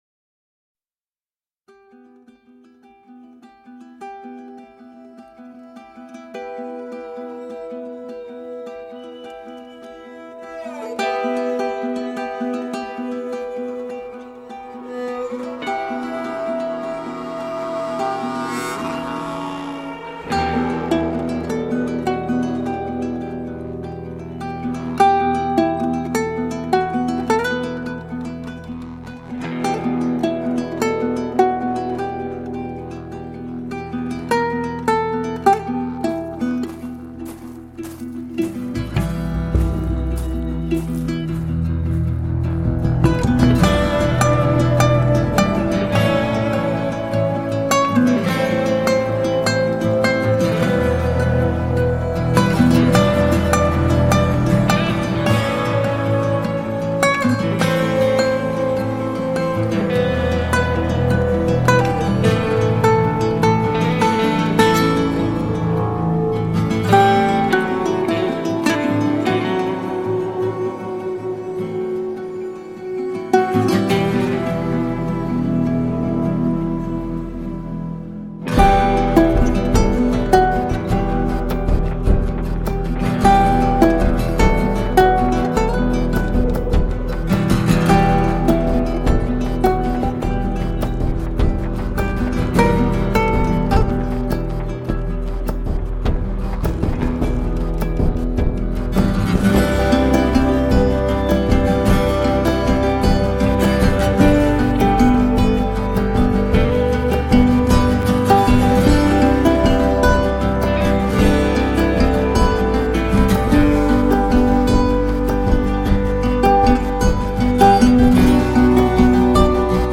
Soundtrack, Post-Apocalyptic